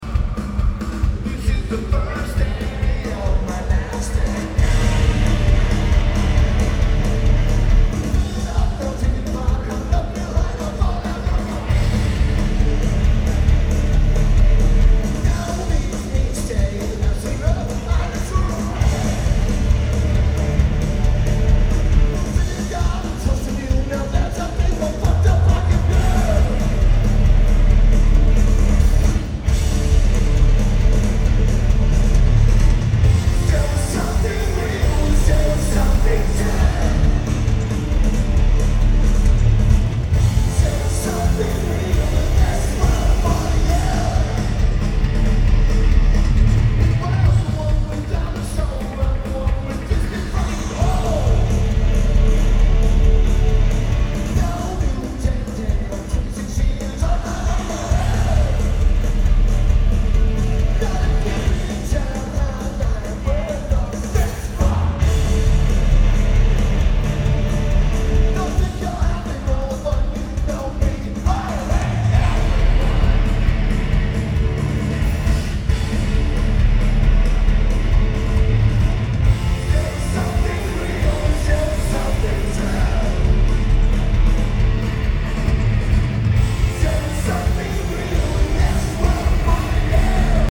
Verizon Wireless Music Center
Lineage: Audio - AUD (DPA 4060 (HEB) + BB + Edirol R09)